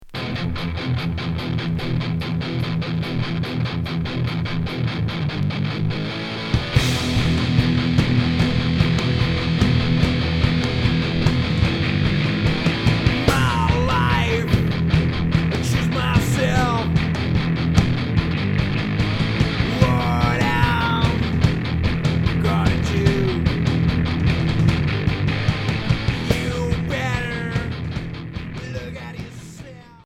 Noise